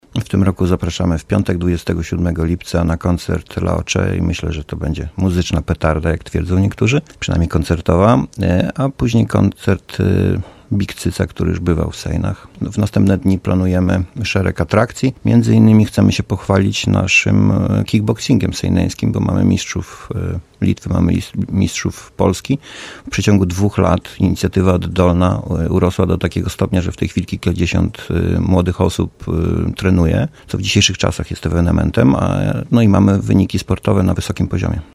Szczegóły wydarzenia przedstawił Arkadiusz Nowalski, burmistrz Sejn.
arkadiusz-nowalski.mp3